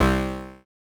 Buzz Error (3).wav